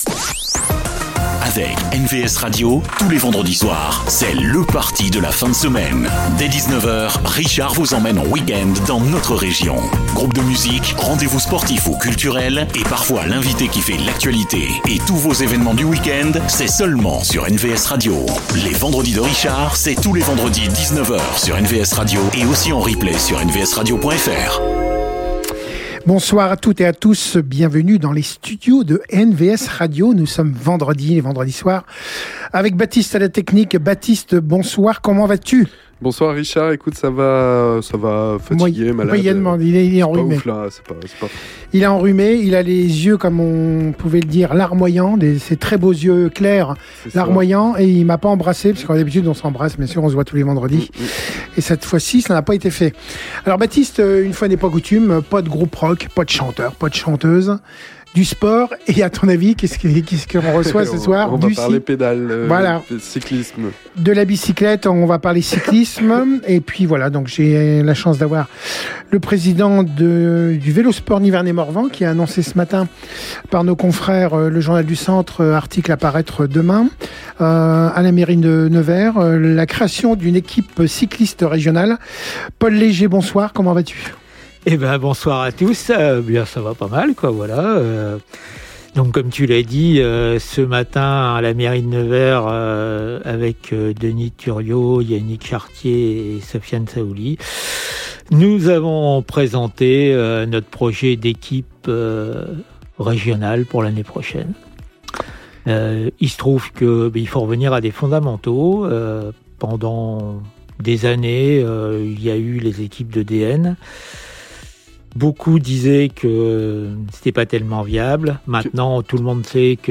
Nous avons eu le plaisir de recevoir en direct l’équipe du Vélo Sport Nivernais !
Une belle interview à (re)découvrir dès maintenant en replay sur NVS Radio.